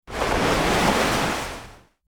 Ocean Shore | TLIU Studios
Category: Nature Mood: Refreshing Editor's Choice